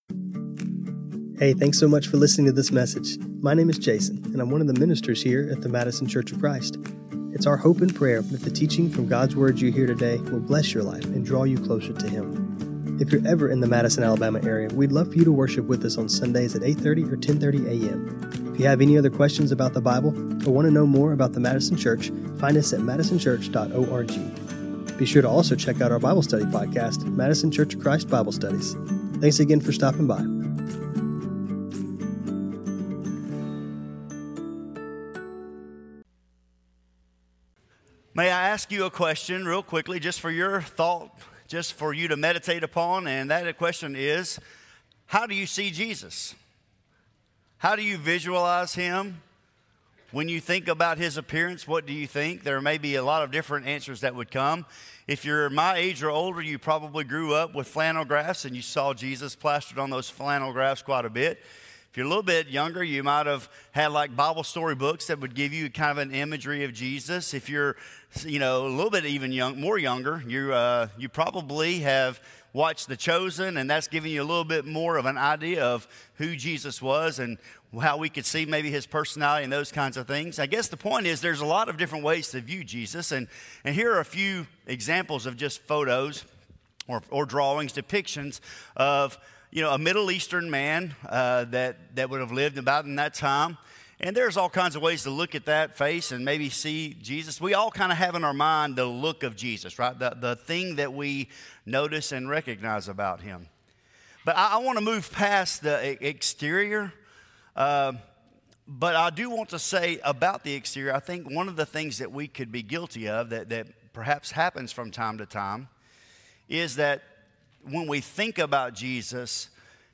Scripture Reading: Matthew 9:9-13 This sermon was recorded on Mar 8, 2026.